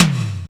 R BAMBTOMLO.wav